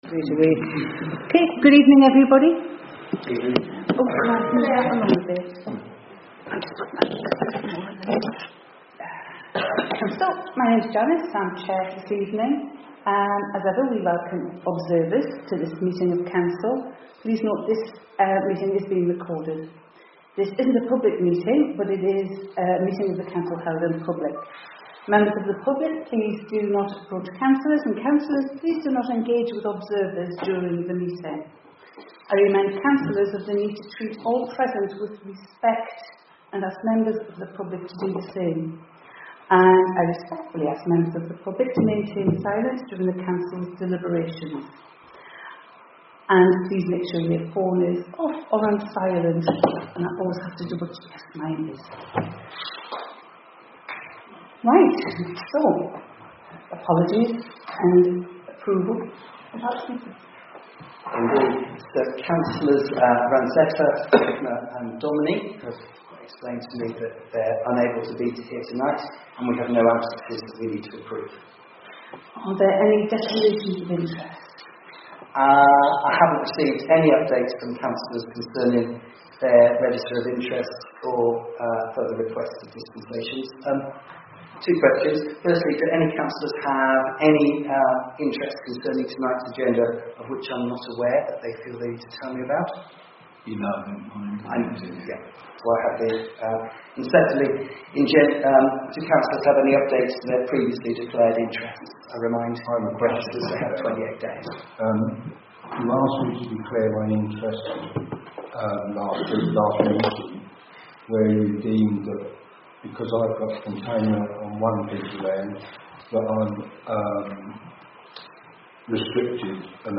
Lavenham Parish Council meets in the Lavenham Village Hall, generally on the first Thursday of every month, at 7.p.m.
Recording of Meeting 2 April 2026